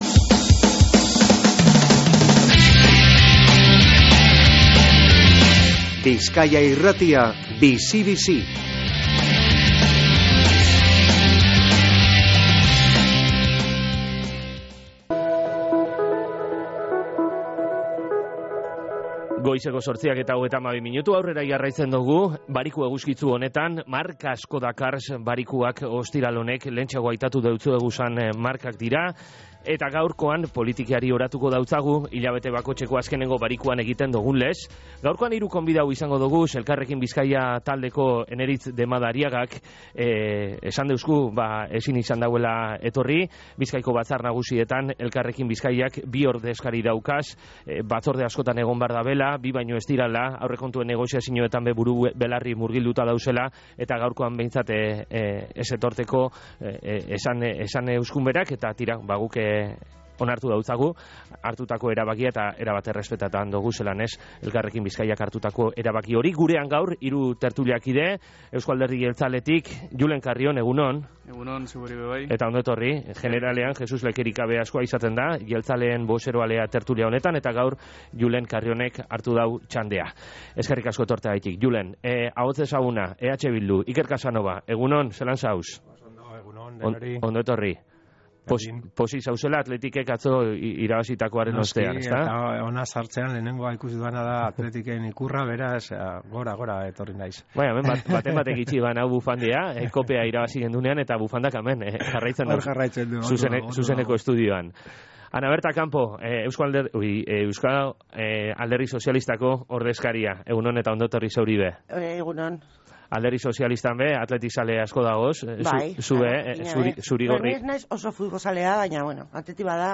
Ana Berta Campo (PSE), Julen Karrion (EAJ) eta Iker Casanova (EH Bildu) izan dira gaurko tertulian